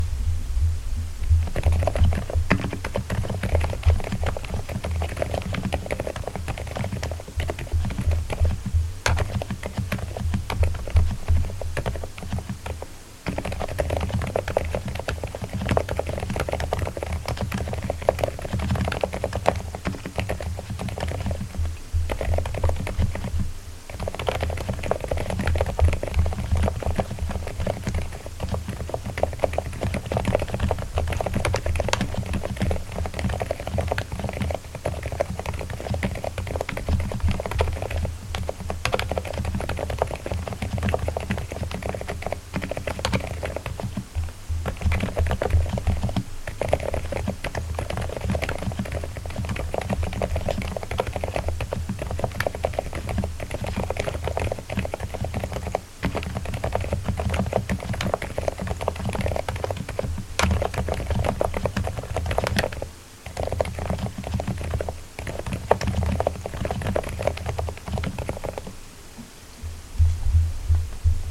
I recorded the keyboard using a Maono PD200W microphone:
As you can hear, the keyboard is pretty much silent. It has a low-frequency component and no high-frequency one, which makes it sound muted and dampened.
Epomaker-TH87-recording.mp3